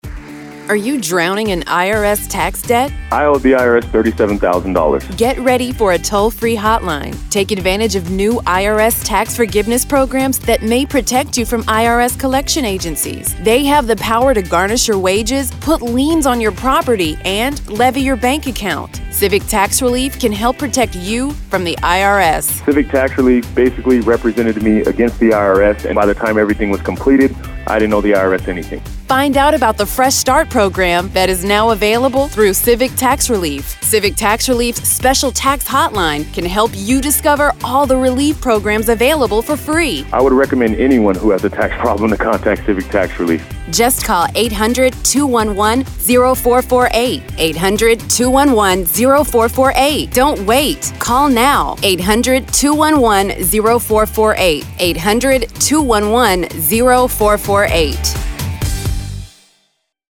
RADIO ADVERTISEMENTS